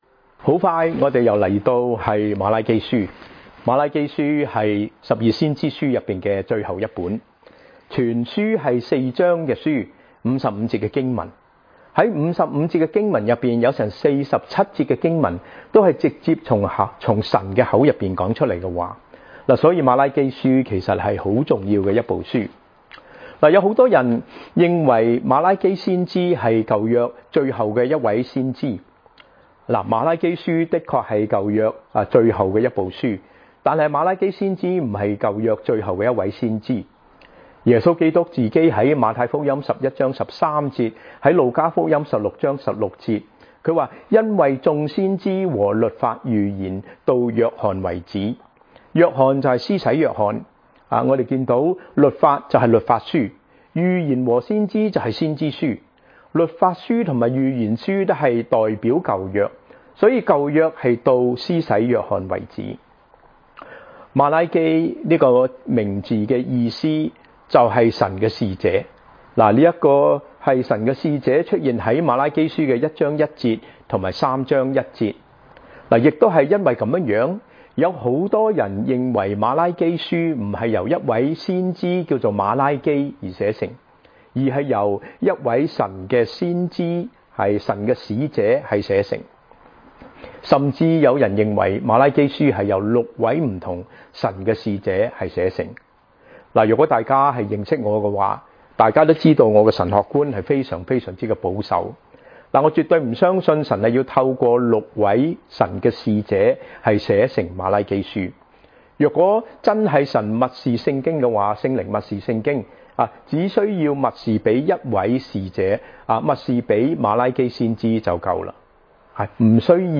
cantonese sermons, sermons in cantonese, chinese sermons, sermons in chinese, christian sermons, cantonese sermon, sermon in cantonese, chinese sermon, sermon in chinese